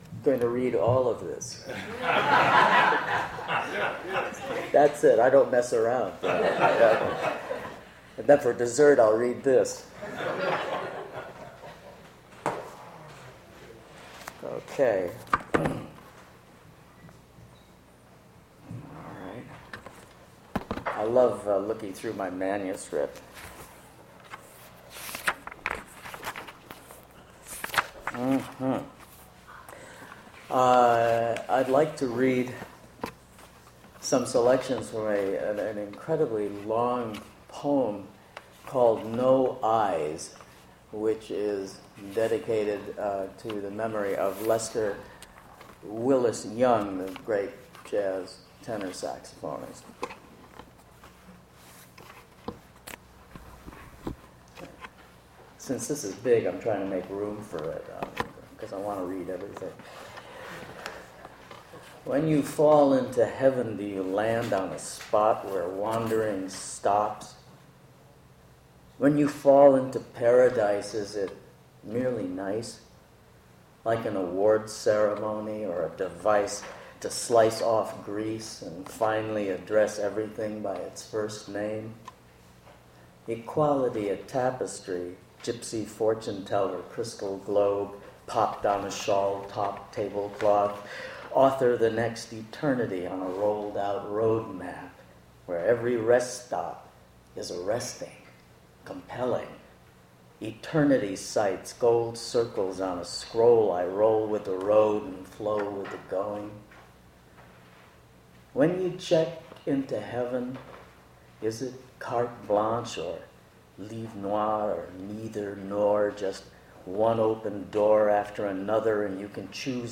Attributes Attribute Name Values Description David Meltzer reading his poetry at Duff's Restaurant.
recording starts in the middle of the performer's introduction